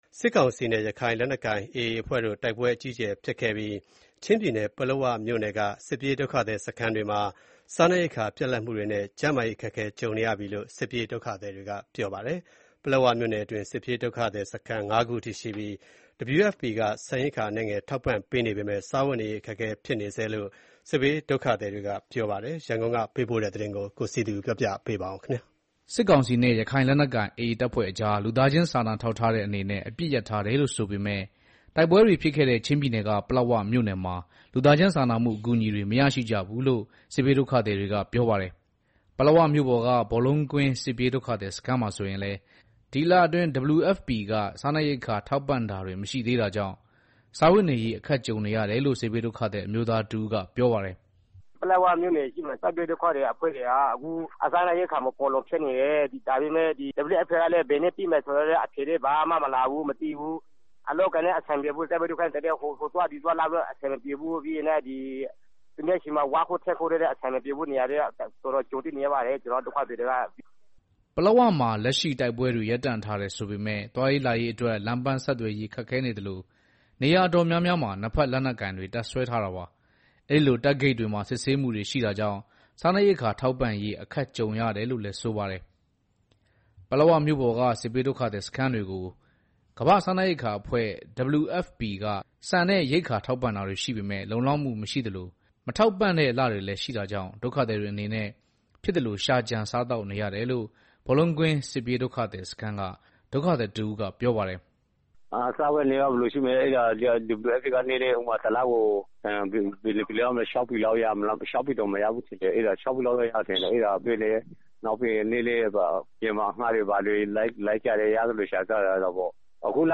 ပလက်ဝမြို့ပေါ်က ဘောလုံးကွင်းစစ်ပြေးဒုက္ခသည်စခန်းမှာဆိုရင်လည်း ဒီလအတွင်း WFPက စားနပ်ရိက္ခာထောက်ပံ့တာတွေမရှိသေးတာကြောင့် စားဝတ်နေရေးအခက်ကြုံနေရတယ်လို့ စစ်ဘေးဒုက္ခသည် အမျိုးသားတဦးကပြောပါတယ်။